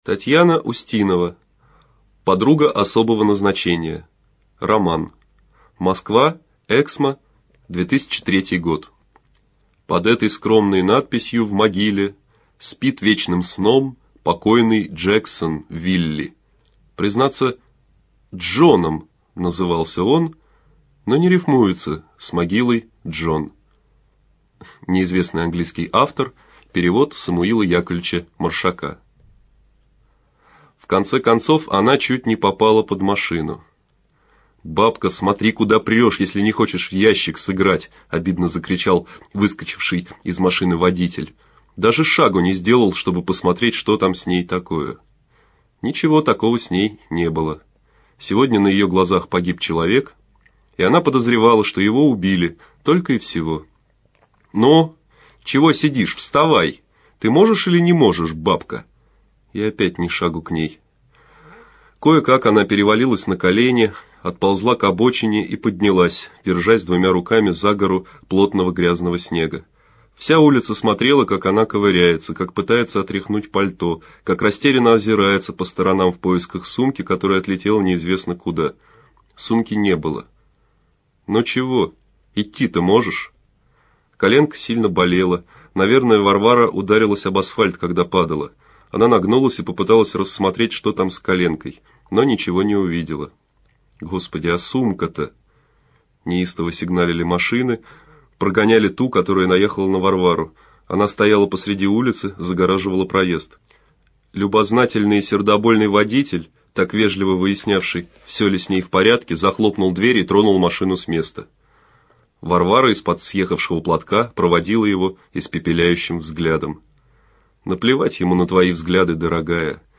Аудиокнига Подруга особого назначения | Библиотека аудиокниг